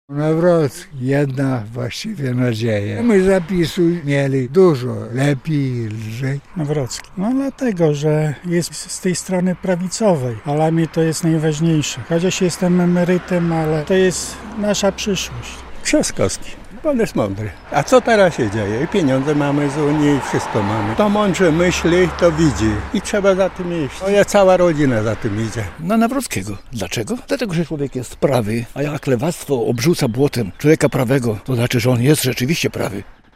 Na kogo głosowali mieszkańcy Białegostoku? - relacja